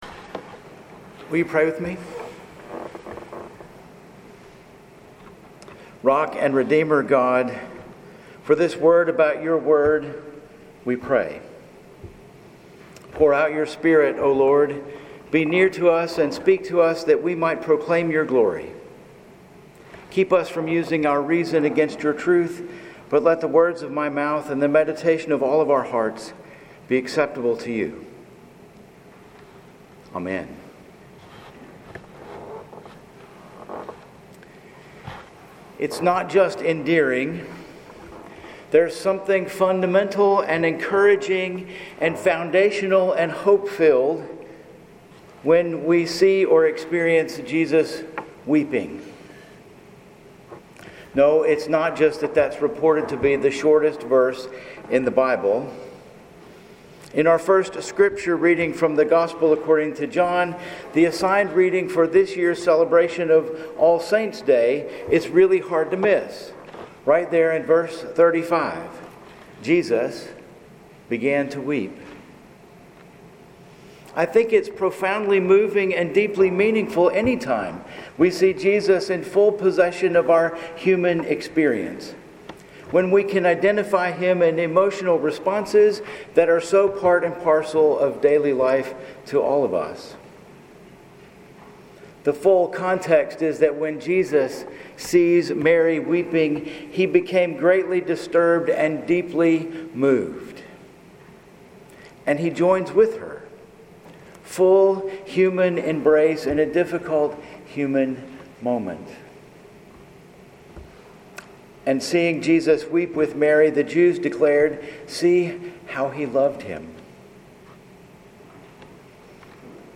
Sermons at First Presbyterian Church El Dorado, Arkansas